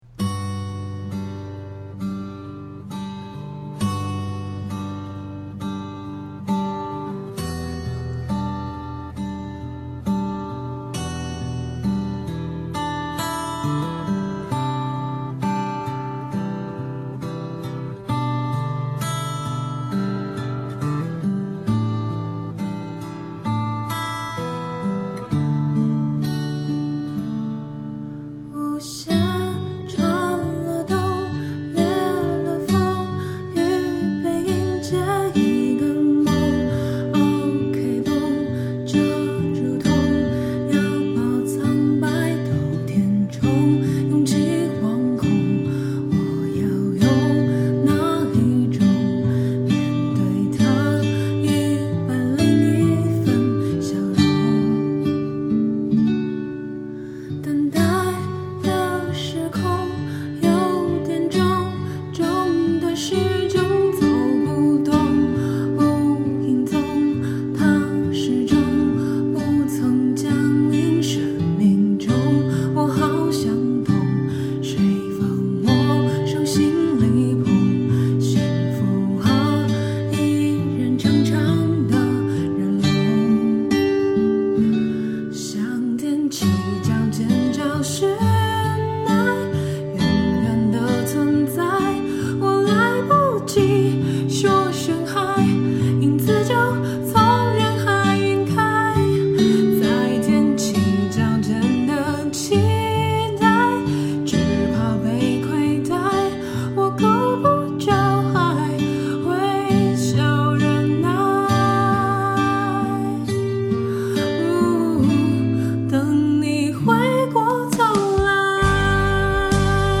但是 lz有点小破音，再接再厉哦！
这首歌有伴奏了哇。。。好棒呀，俺也喜欢这首曲子，唱得很有味道呀